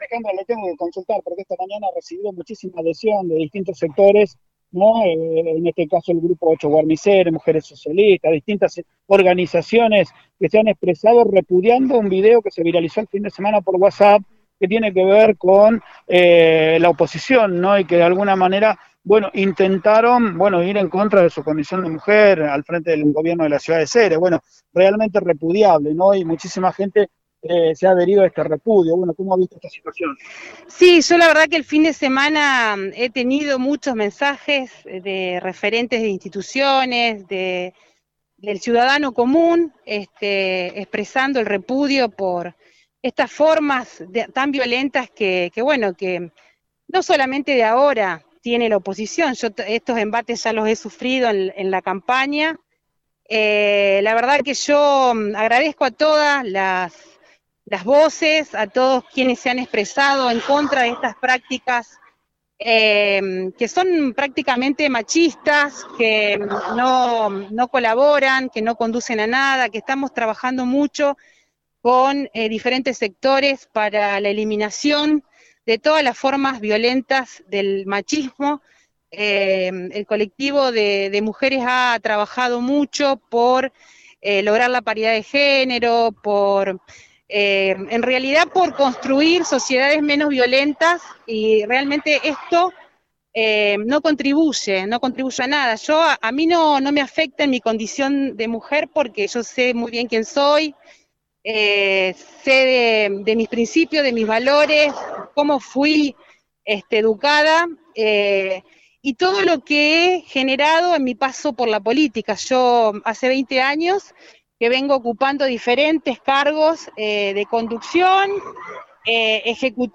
Escucha a la Intendente Dupouy: